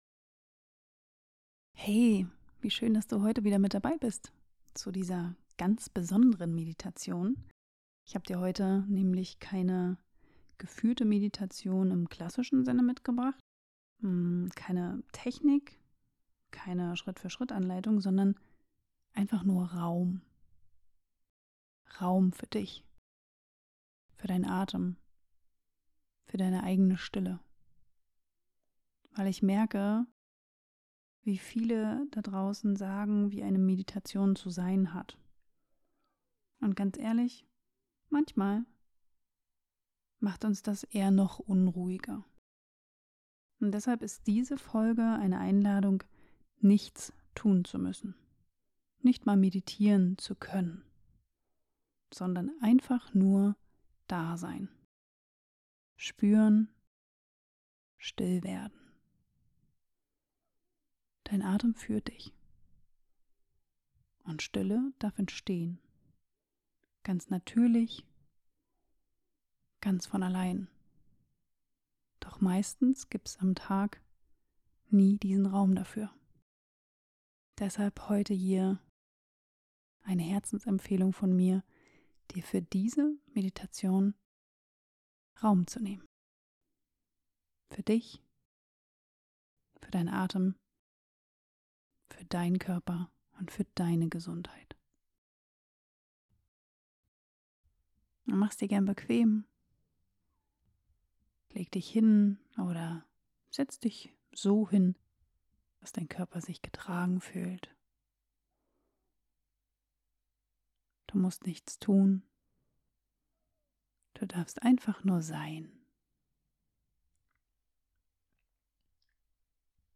Keine Ansage, was du fühlen "sollst". Nur deinen Atem.